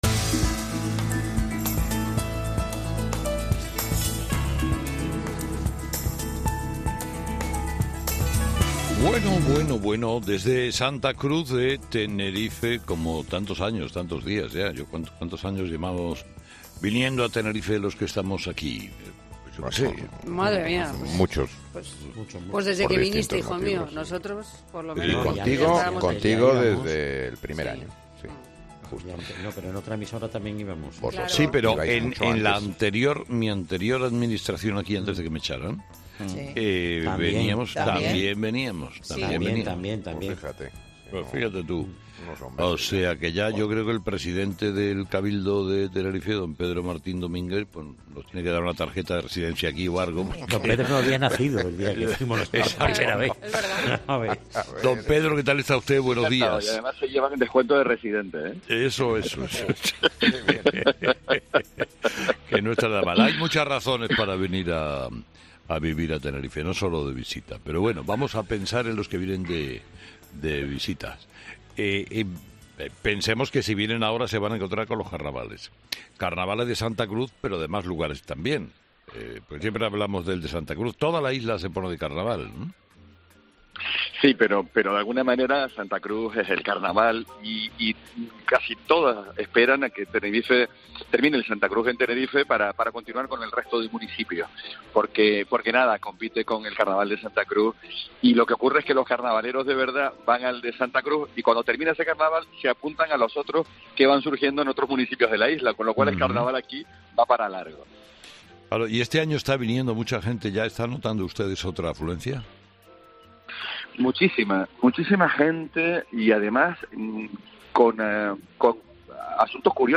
El presidente del Cabildo de Tenerife ha explicado en 'Herrera en COPE' que el nicho de turistas se esta expandiéndo a países como Israel, gracias a los carnavales
Como suele ser habitual cada año, este viernes, 'Herrera en COPE' se desplaza hasta la isla en pleno comienzo de su fiesta mayor, los carnavales.